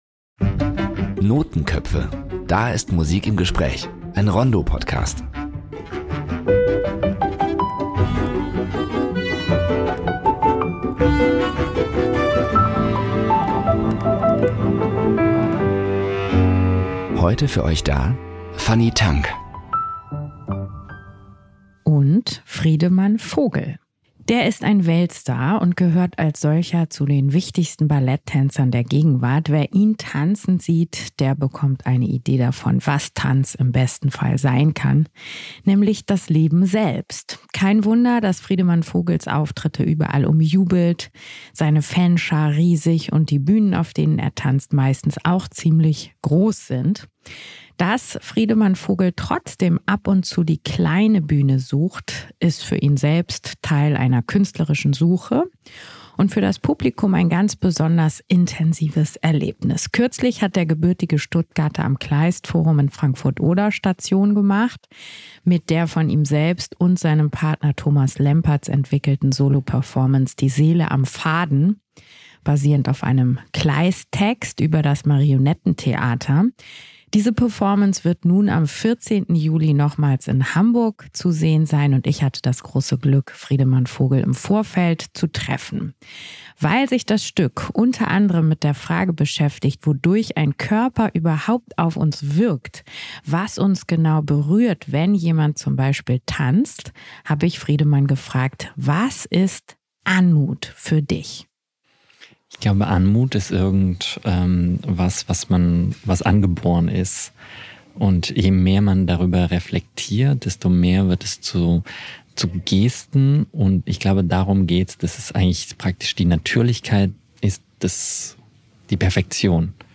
Zum Beispiel am Kleist-Forum in Frankfurt (Oder), wo er gerade mit seiner Solo-Performance „Die Seele am Faden“ zu erleben ist.